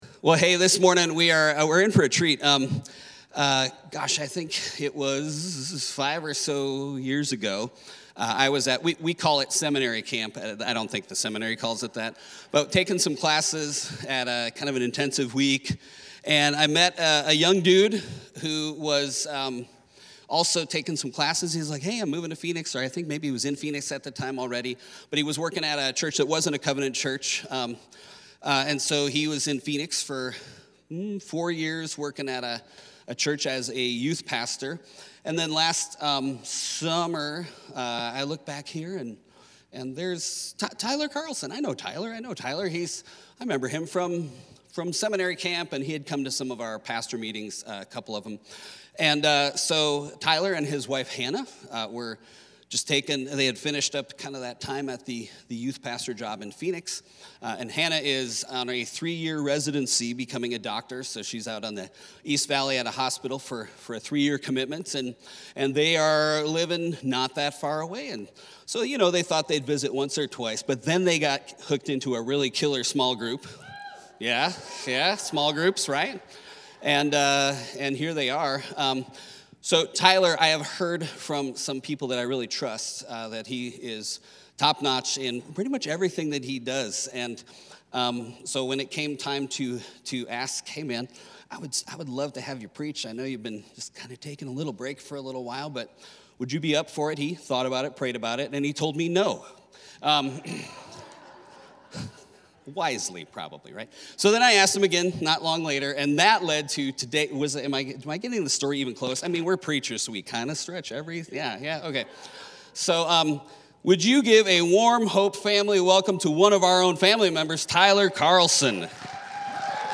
Weekly messages from HOPE Covenant Church in Chandler AZ